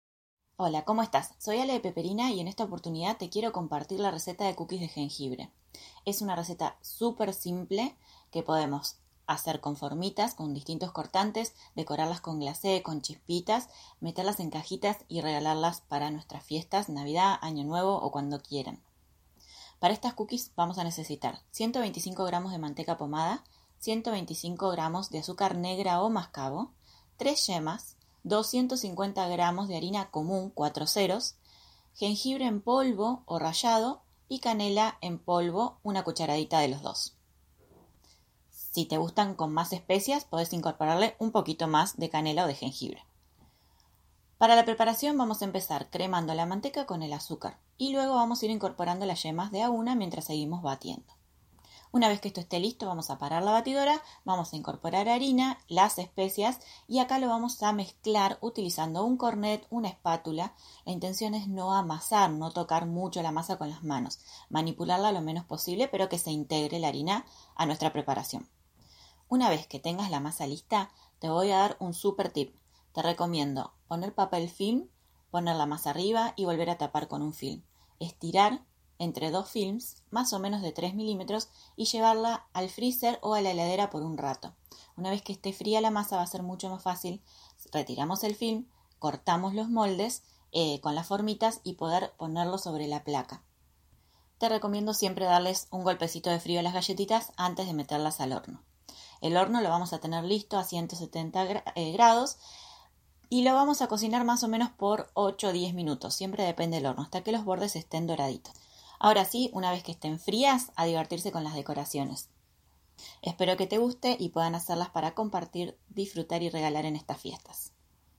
Audio receta de cookies de jengibre